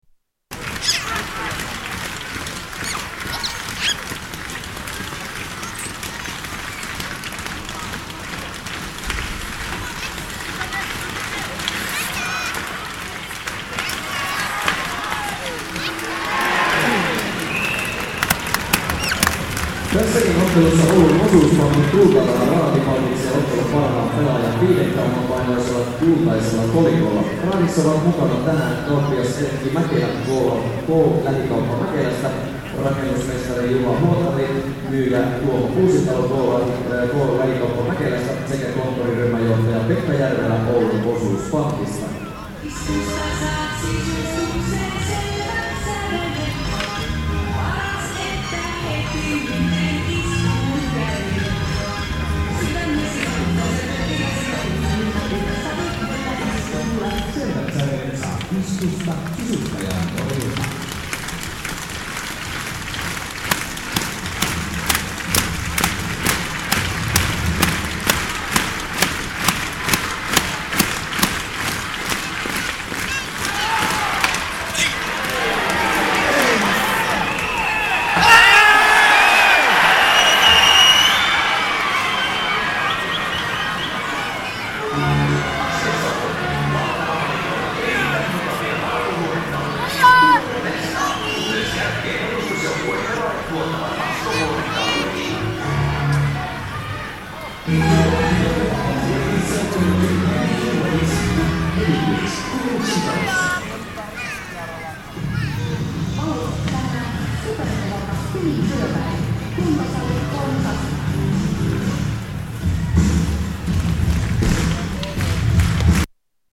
Local ice hockey
Tags: Travel Sounds of Finland Finland Vacation Helsinki